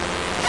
科幻空间外星人声景
描述：科幻空间外星人声景.外星人和空间噪音 .
Tag: 科幻 啁啾 叽叽喳喳 古典的 声景 空间 外星人